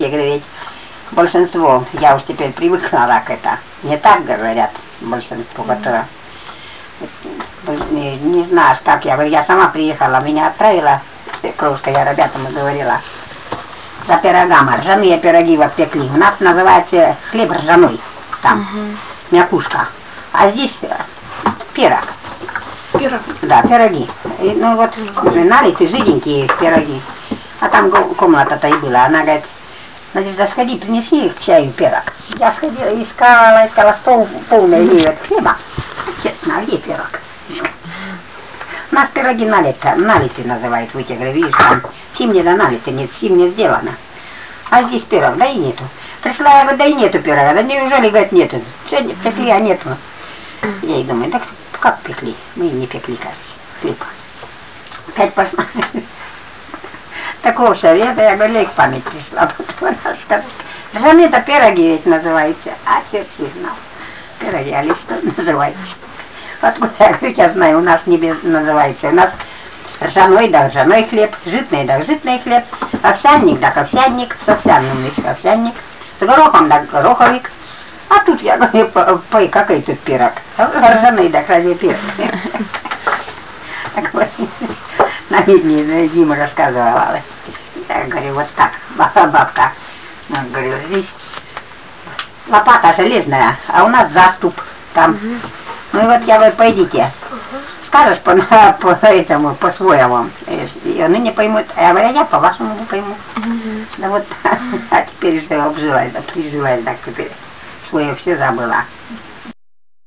Obrazcy sovremennyh russkih govorov Karelii